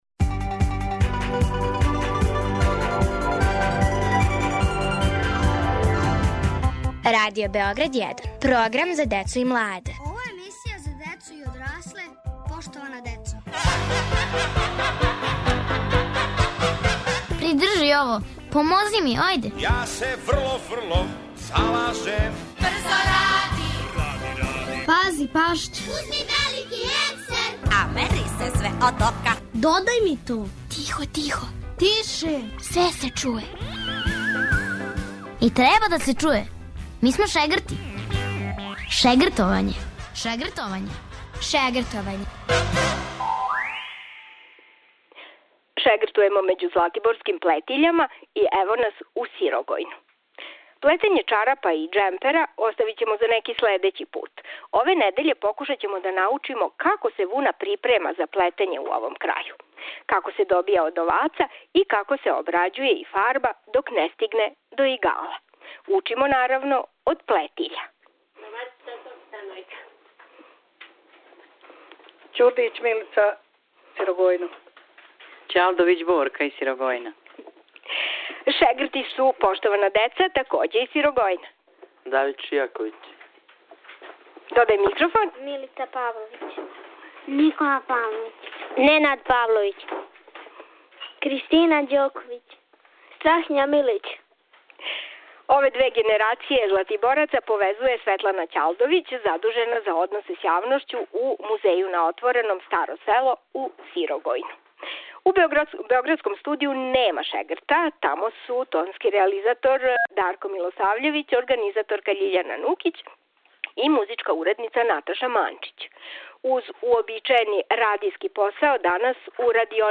Емисија иде узиво из Музеја Старо село у Сирогојну. Деца из Чајетине и Ариља шегртоваће код златиборских плетиља и учити како се припрема вуна и фарба плетиво.